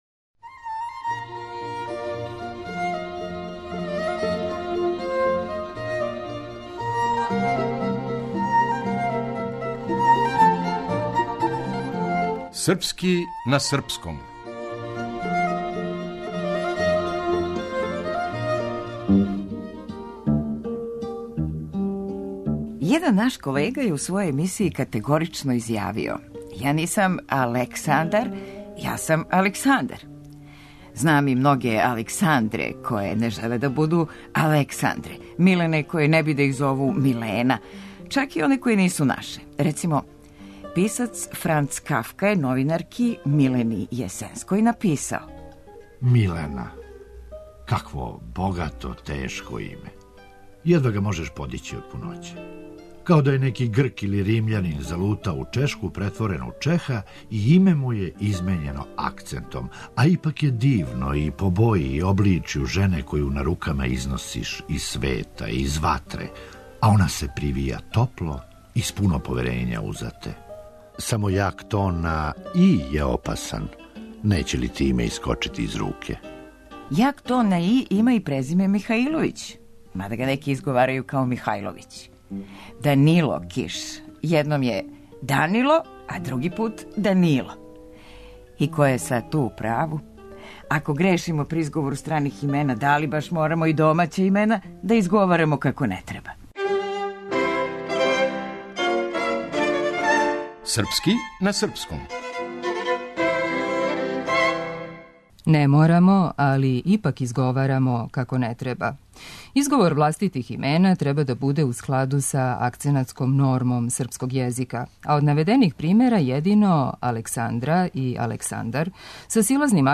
Драмски уметници